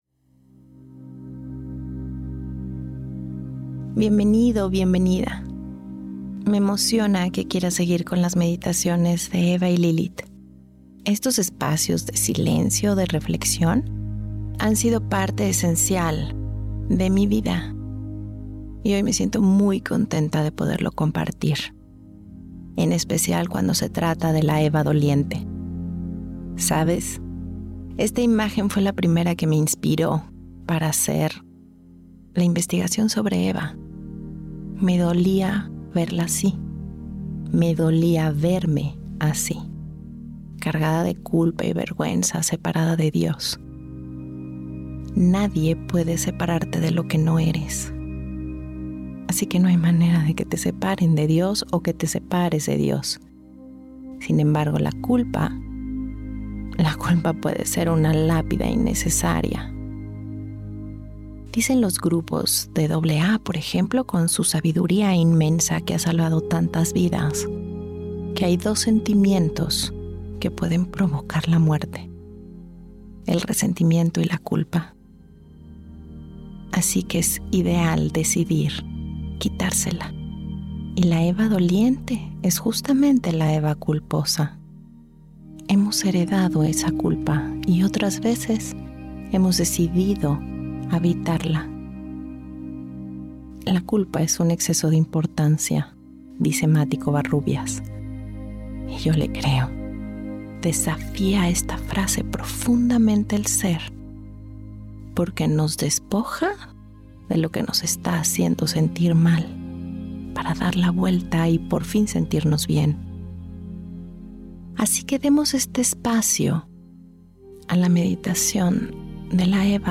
Esta meditación te invita a separarte de la culpa y asumir con responsabilidad los hechos para abrazar el error y fortalecer nuestra sabiduría interior.